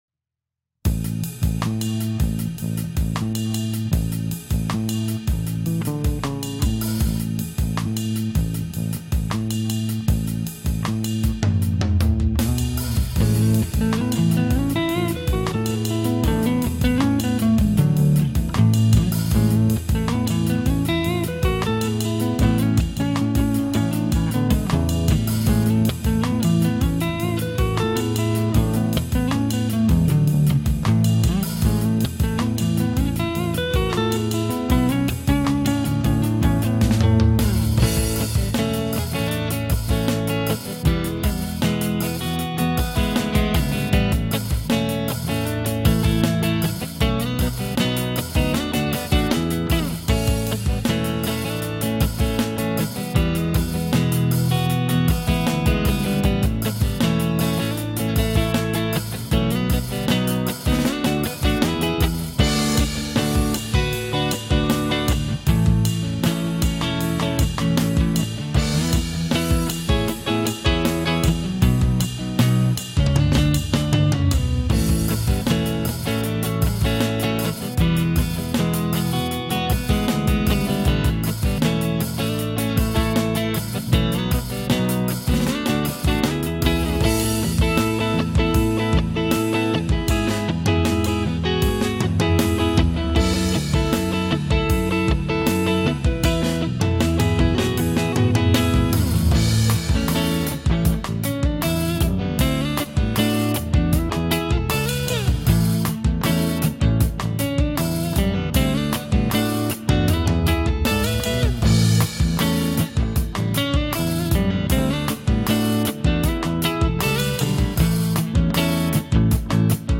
Guitars and Bass